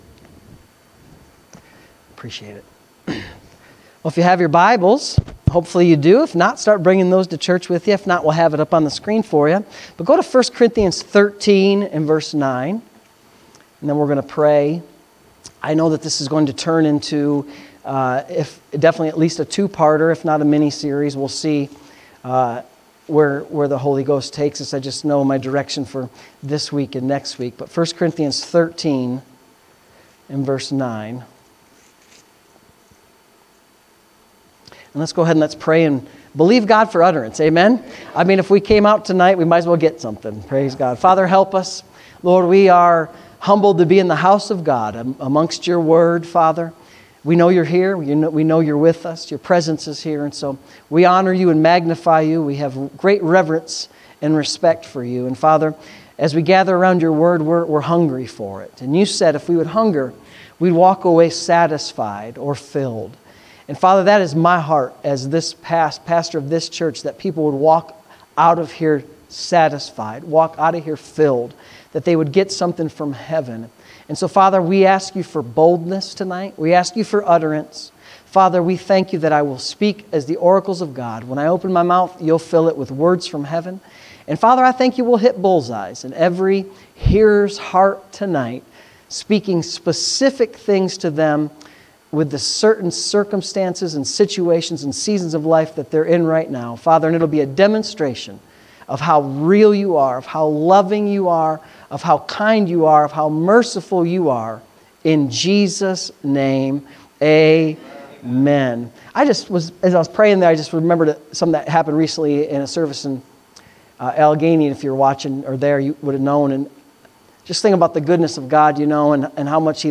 Wednesday Evening Services